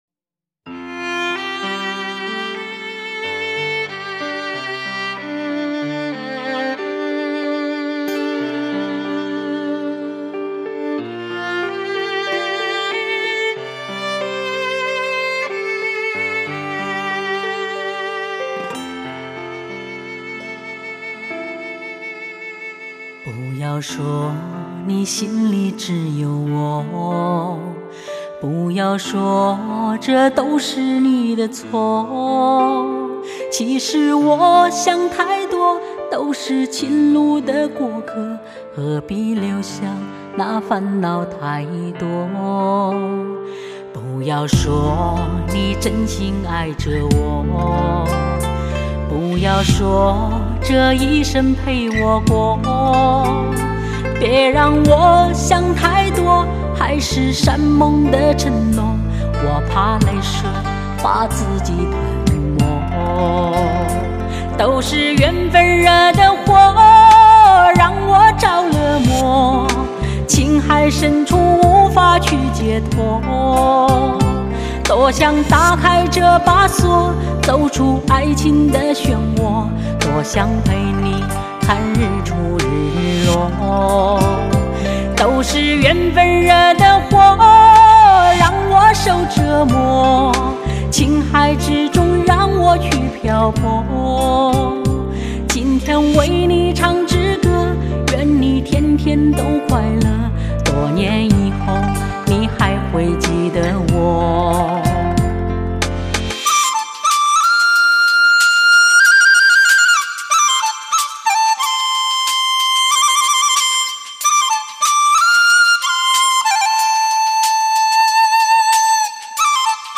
天籁人声